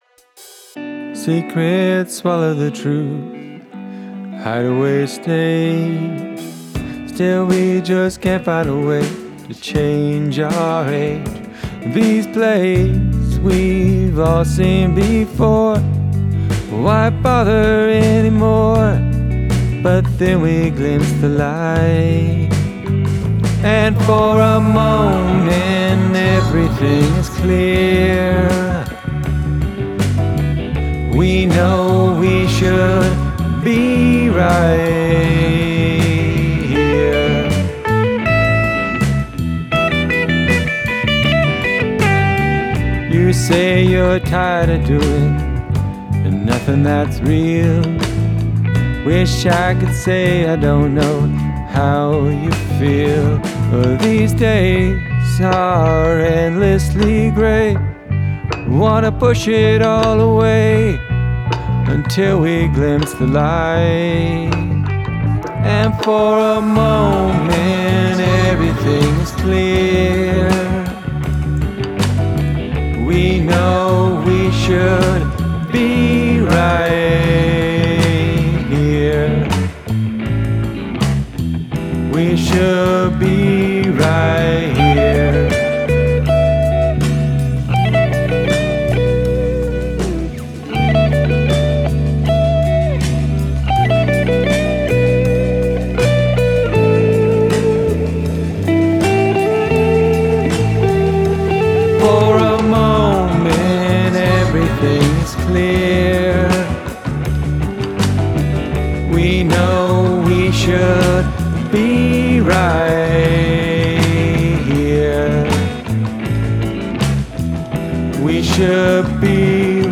Have a guest play a household item on the track